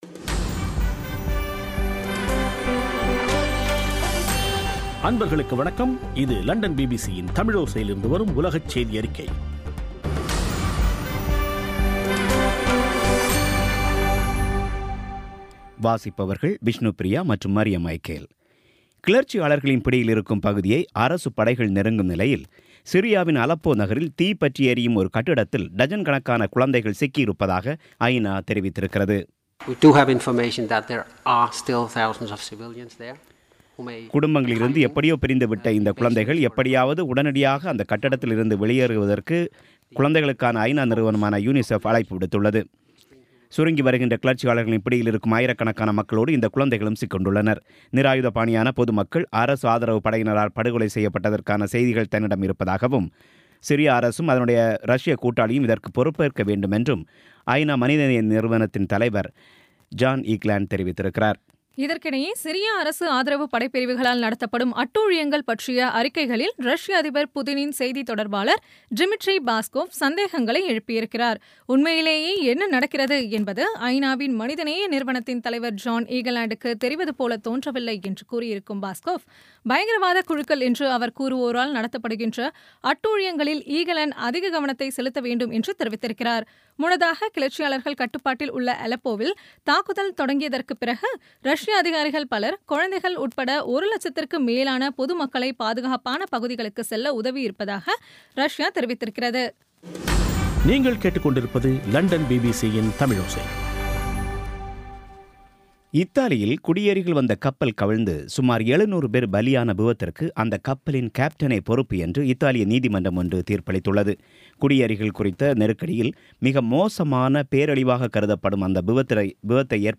பிபிசி தமிழோசைசெய்தியறிக்கை (13/12/2016)